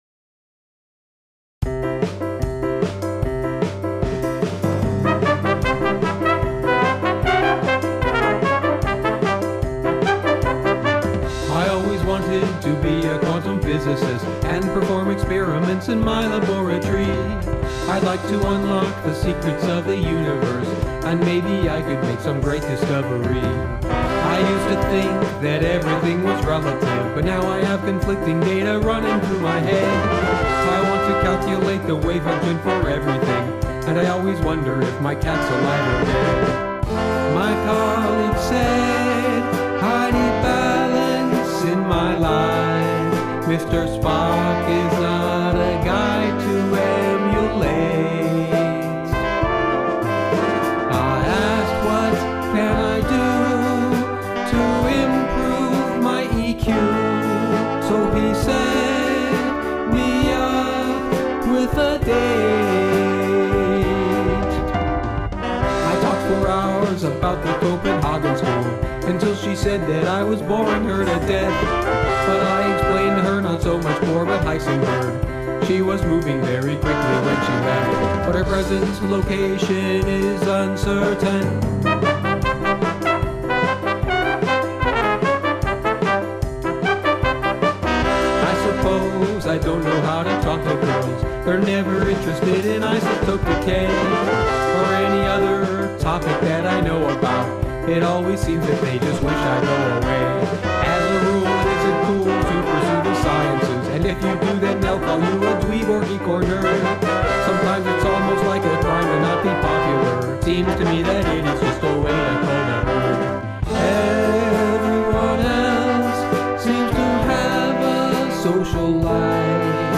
Quirky & geeky both come to mind.